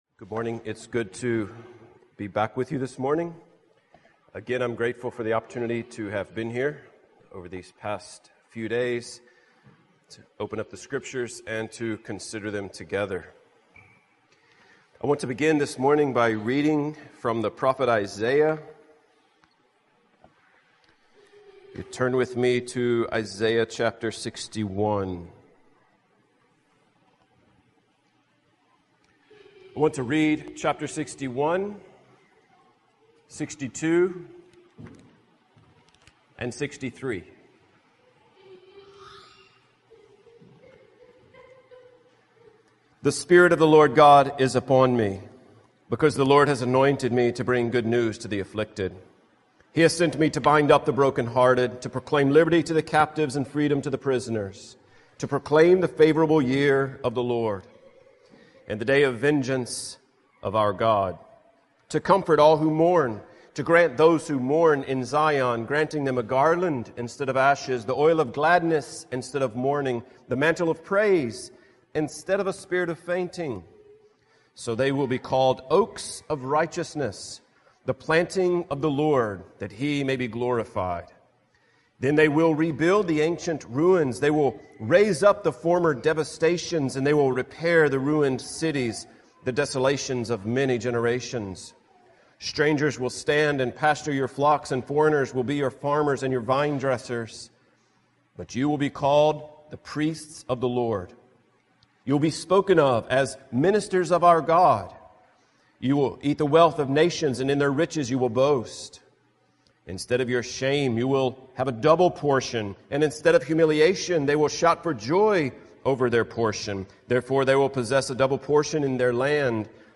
2019 Category: Full Sermons God makes amazing and incredible promises to us in His Word.